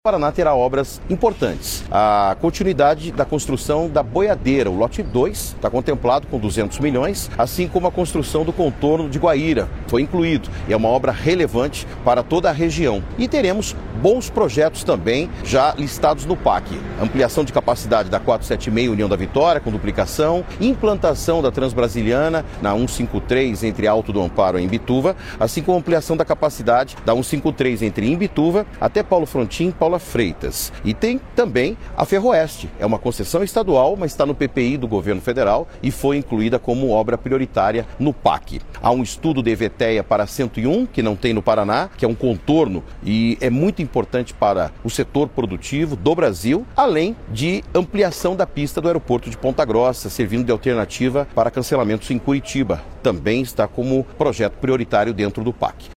Sonora do secretário de Infraestrutura e Logística, Sandro Alex, sobre os projetos indicados pelo Paraná para estarem no novo PAC